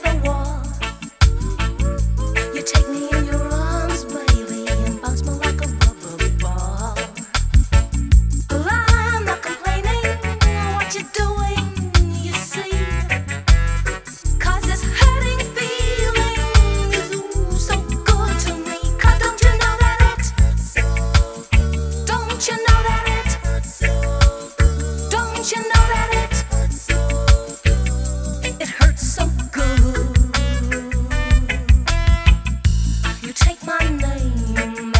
Жанр: Реггетон
# Reggae